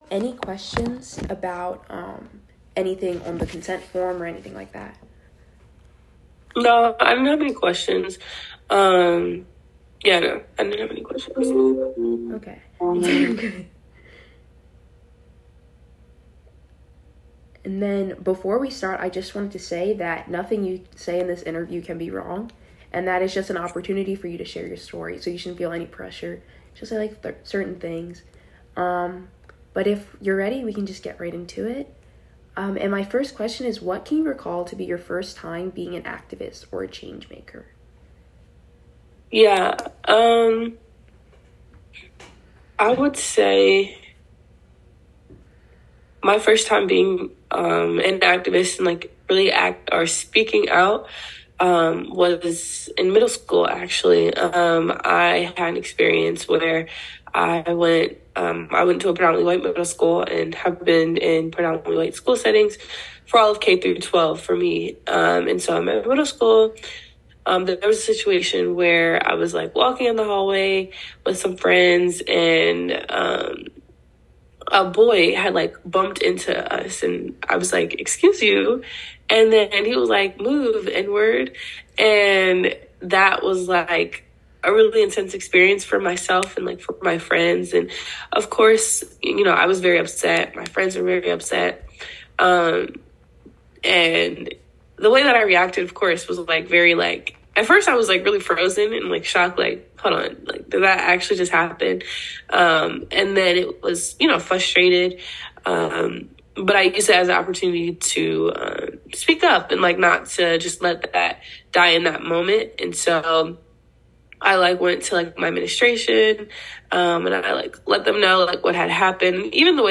An interview
conducted via Zoom